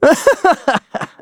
Kibera-Vox_Happy3.wav